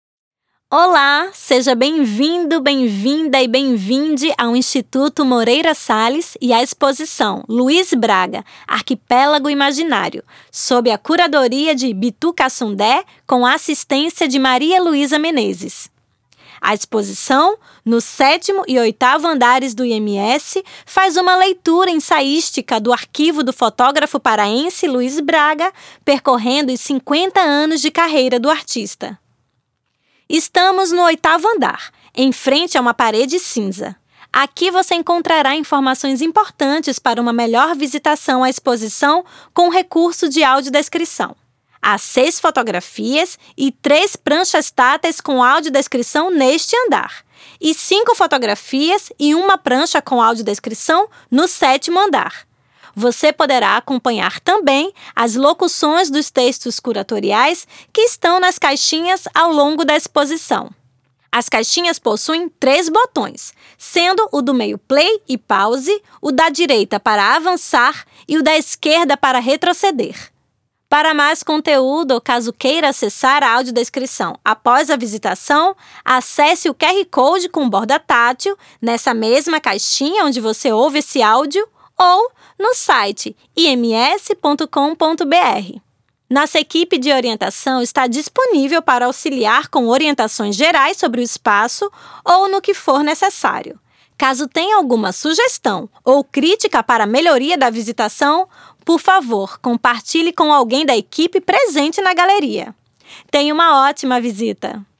Luiz Braga – Arquipélago imaginário - Audiodescrição | Estação 01 - Instituto Moreira Salles